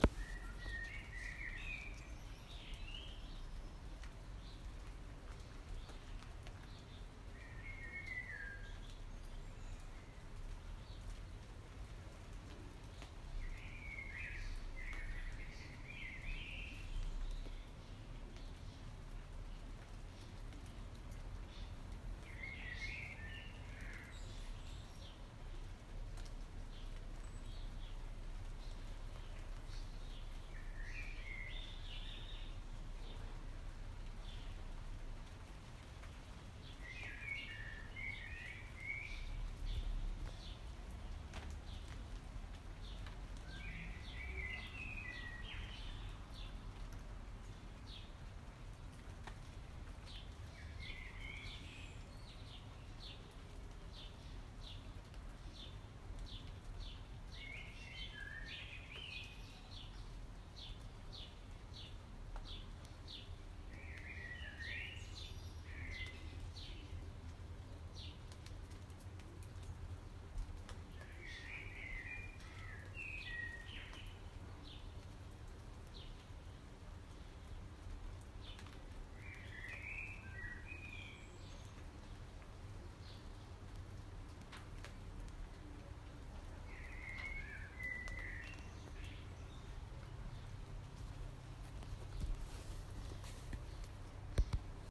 Blackbird in the rain, Friday 12 May 2017